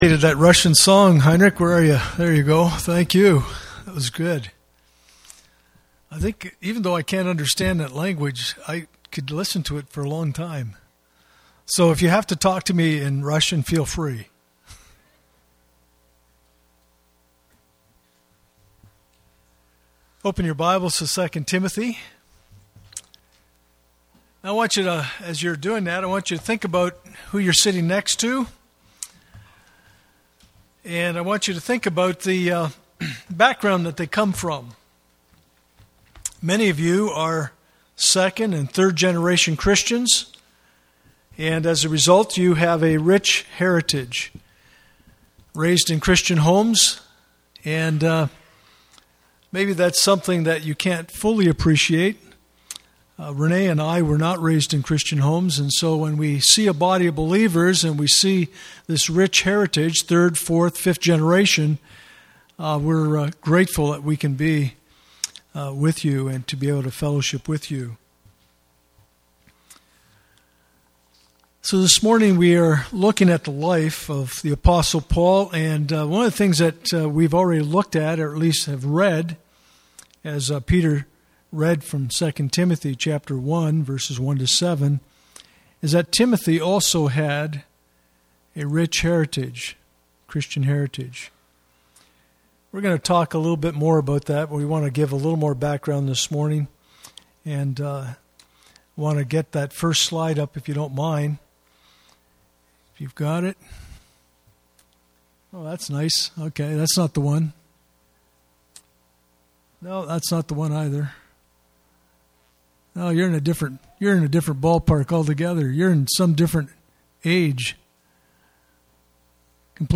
2 Timothy 1:1-7 Service Type: Sunday Morning « Paul